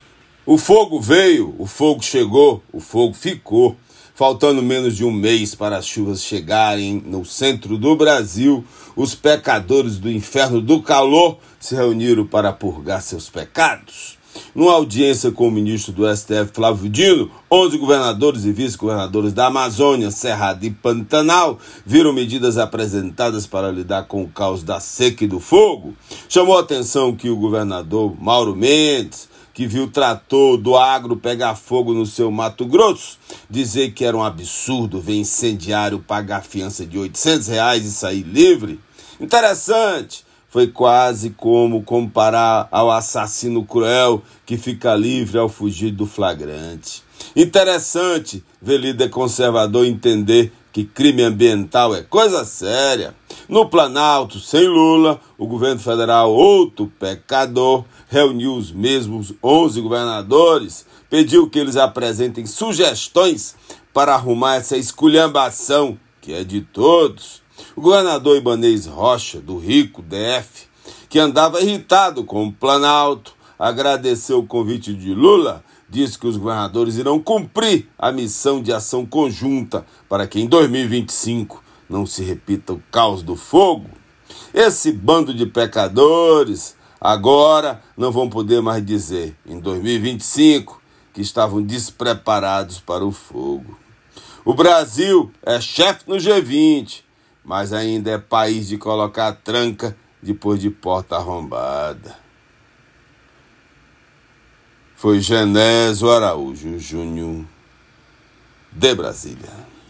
direto de Brasília.